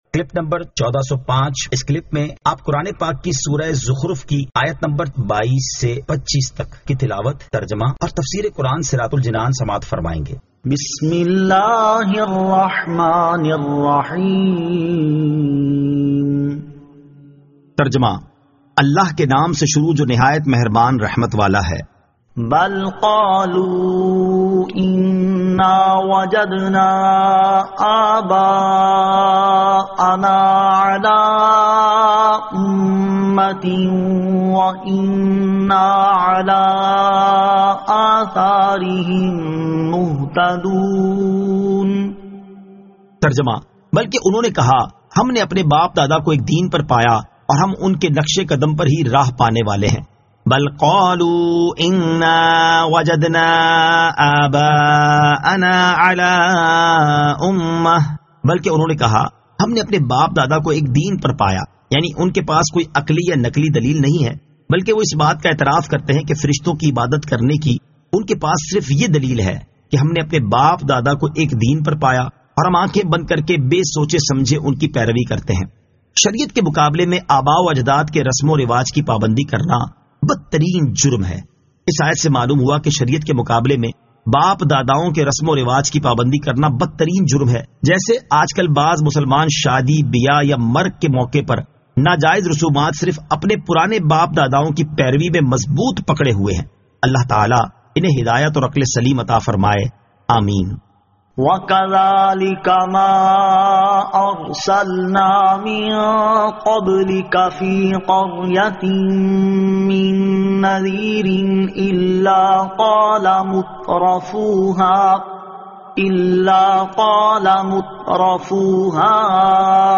Surah Az-Zukhruf 22 To 25 Tilawat , Tarjama , Tafseer